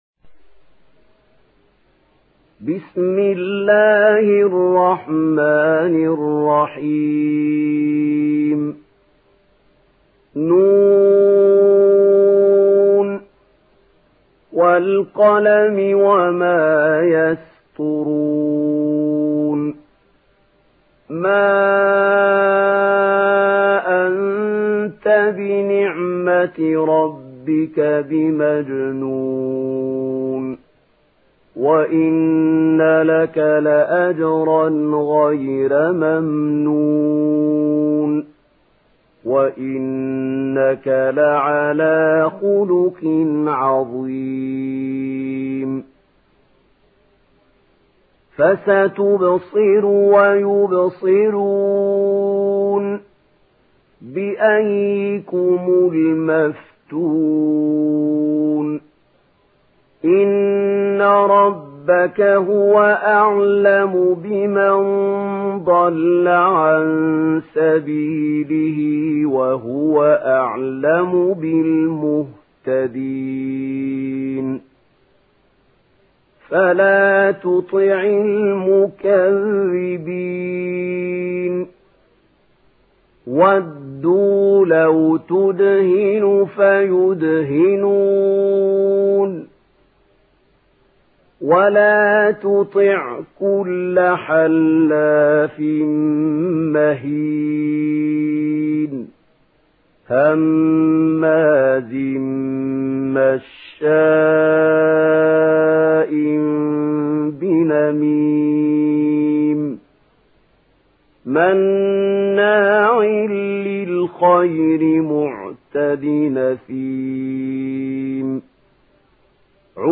Surah Kalem MP3 in the Voice of Mahmoud Khalil Al-Hussary in Warsh Narration
Surah Kalem MP3 by Mahmoud Khalil Al-Hussary in Warsh An Nafi narration. Listen and download the full recitation in MP3 format via direct and fast links in multiple qualities to your mobile phone.